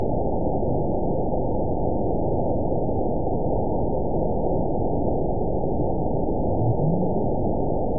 event 921817 date 12/19/24 time 06:47:18 GMT (4 months, 2 weeks ago) score 9.45 location TSS-AB01 detected by nrw target species NRW annotations +NRW Spectrogram: Frequency (kHz) vs. Time (s) audio not available .wav